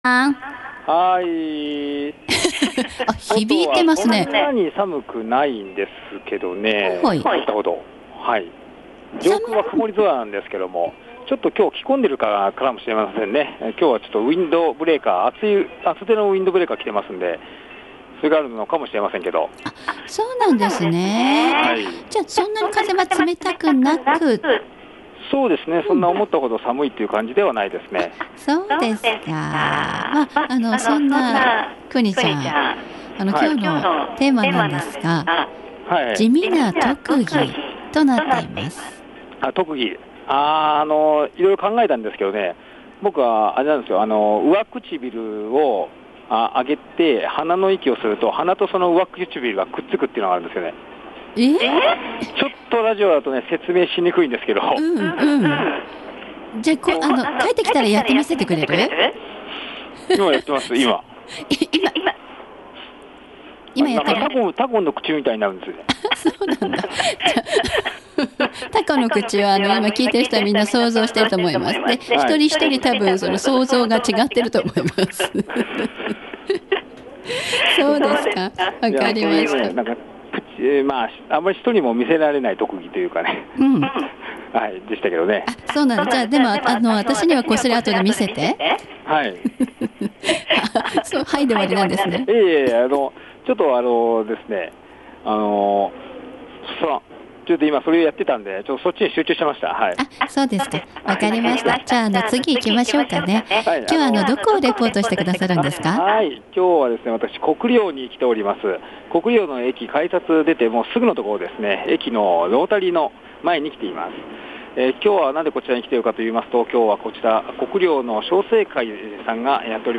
X’masフェスティバルin国領2014の歳末大売出し抽選会会場からお送りしました。